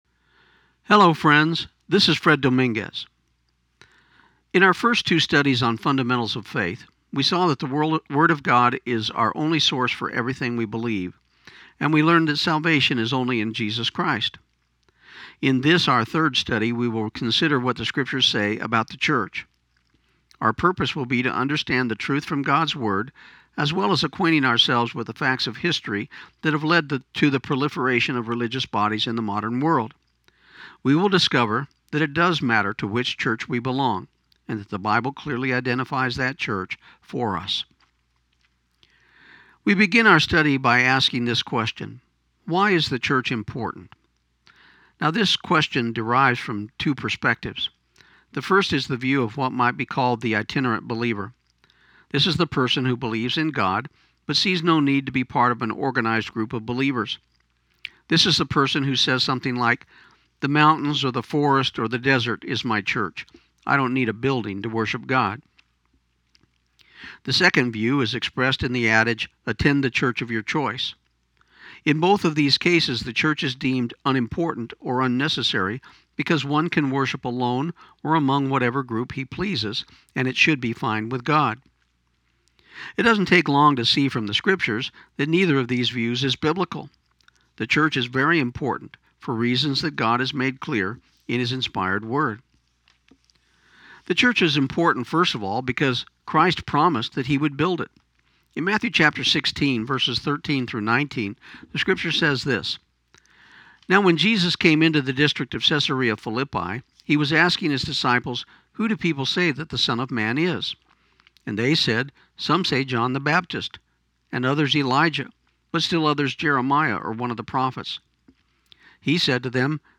This program aired on KIUN 1400 AM in Pecos, TX on January 9, 2015.